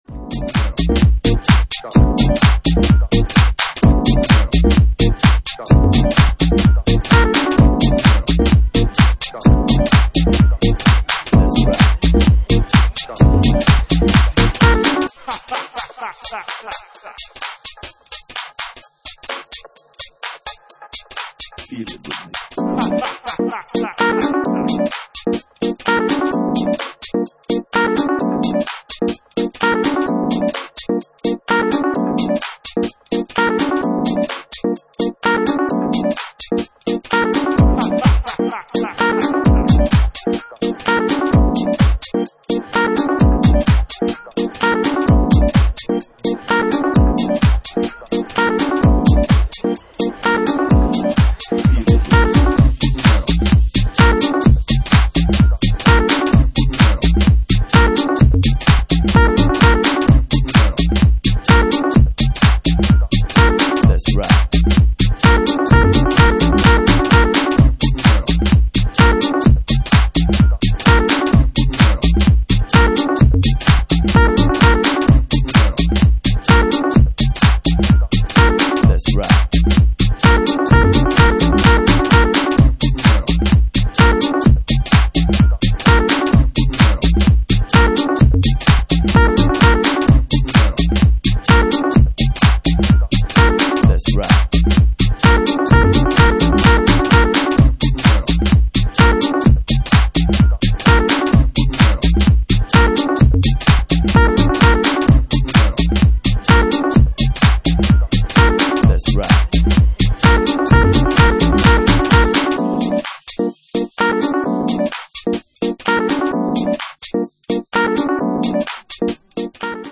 Genre:Jacking House
Jacking House at 129 bpm
Original Mix